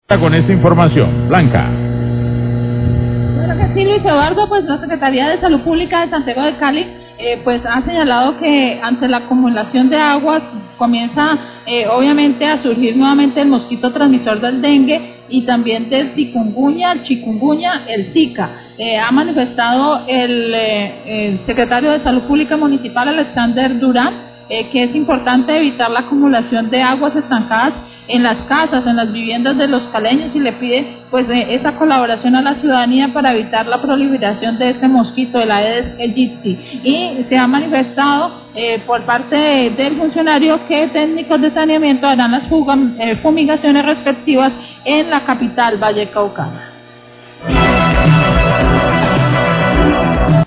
NOTICIAS DE CALIDAD